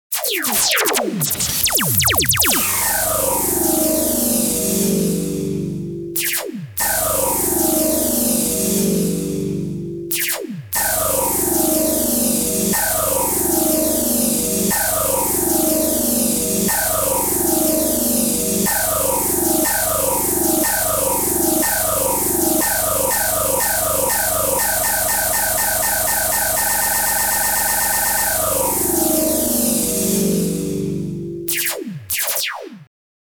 Звуки инопланетян
На этой странице собраны необычные аудиозаписи, имитирующие голоса и технологии внеземных существ.